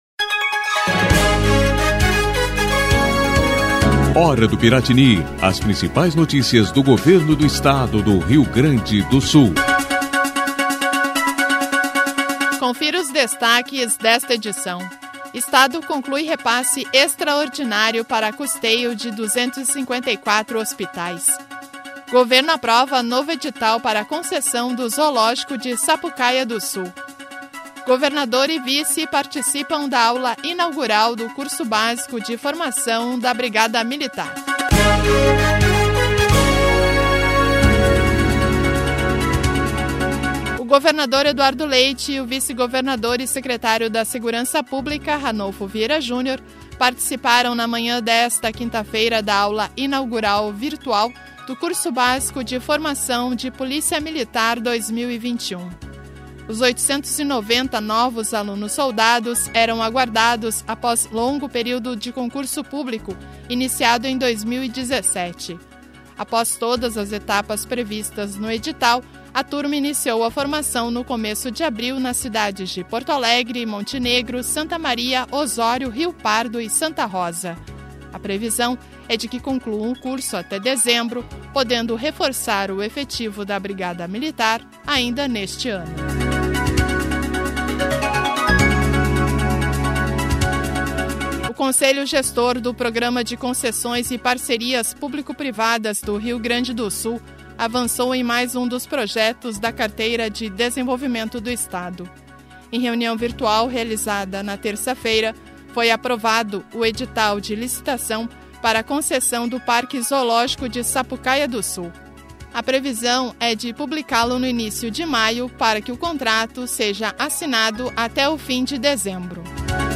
A Hora do Piratini é uma síntese de notícias do Governo do Estado, produzida pela Secretaria de Comunicação.